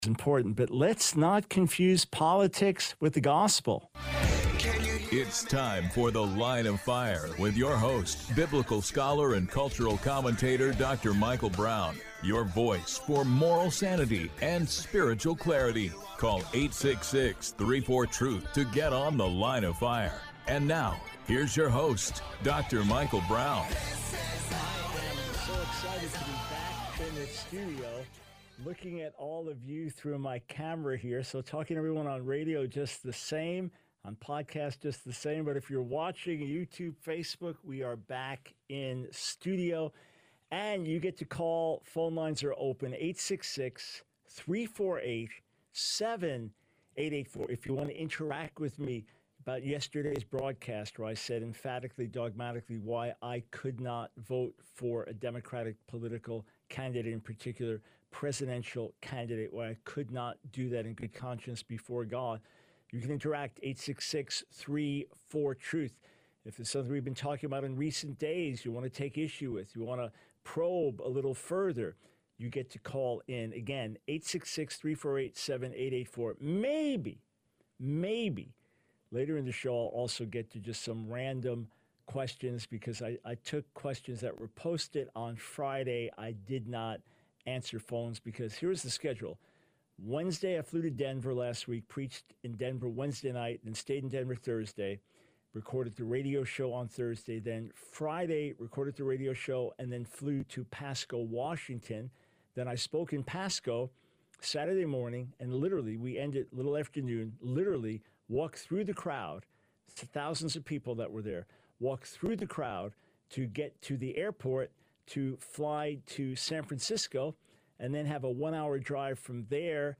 The Line of Fire Radio Broadcast for 08/20/24.